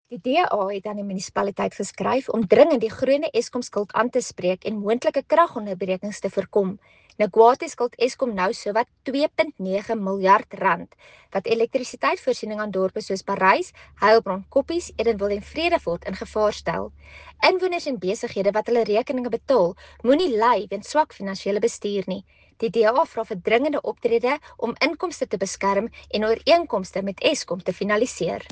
Afrikaans soundbites by Cllr Carina Serfontein and